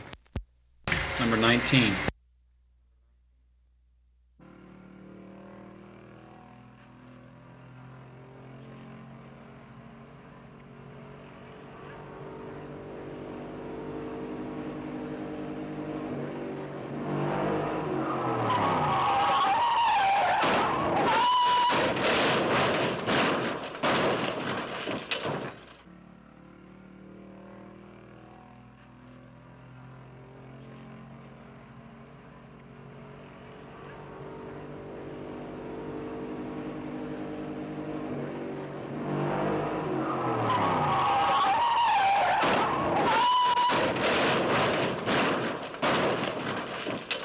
古老的碰撞 " G2619长长的马达声和碰撞声
描述：低引擎隆隆声即将来临，沿着漫长的道路或上坡行驶，非常尖锐的尖叫声和模糊的撞击金属物体。 这些是20世纪30年代和20世纪30年代原始硝酸盐光学好莱坞声音效果的高质量副本。 40年代，在20世纪70年代早期转移到全轨磁带。我已将它们数字化以便保存，但它们尚未恢复并且有一些噪音。